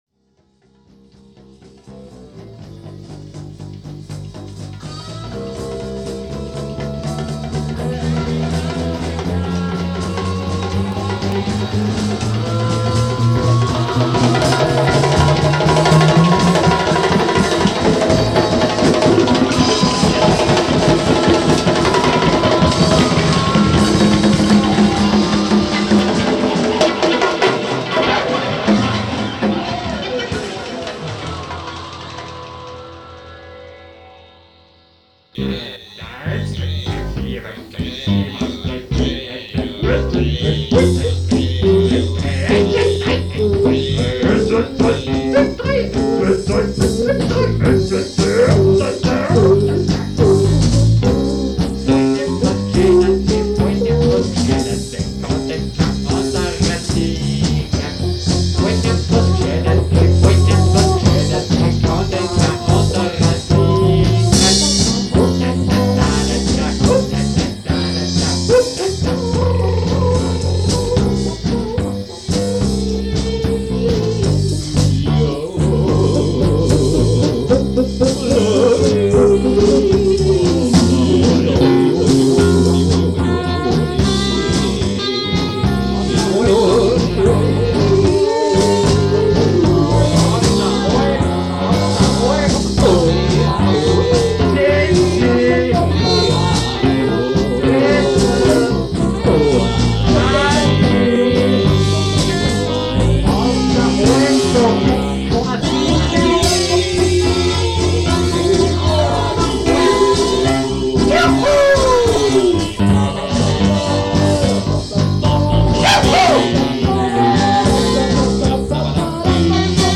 e-bass
guitar
voc, perc.
perc., sax, flute
tuba, microsynth
drums, perc.
Cut from standard cassette-material, live as recorded